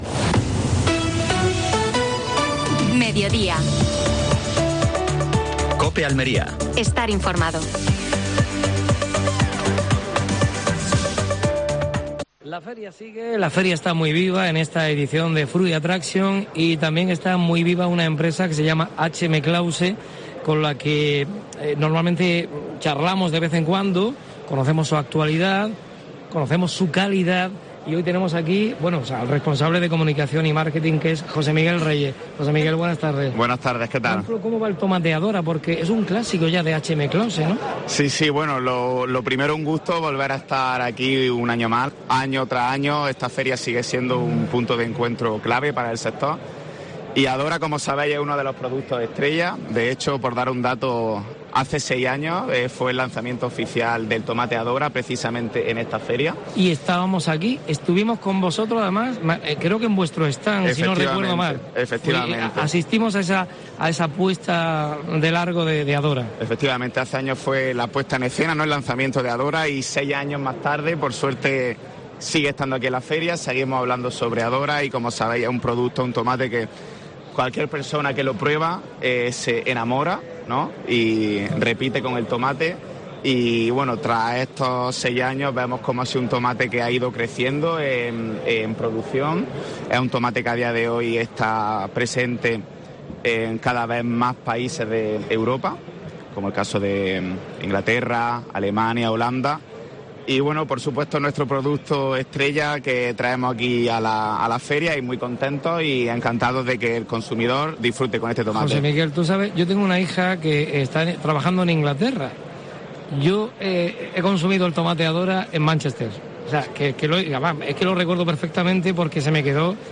AUDIO: Programa especial desde Fruit Attraction (Madrid).